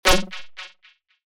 Rave One Shot 9 F#
Rave-One-Shot-9-F.mp3